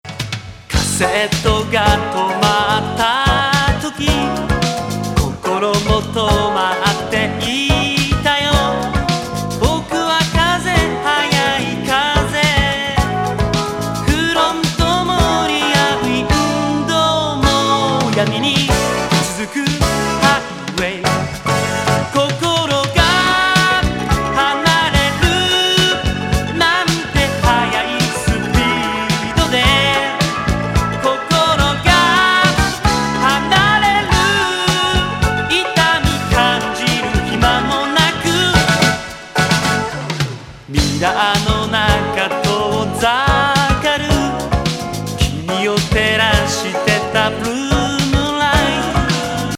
アーバン・サマー・ブリージンAOR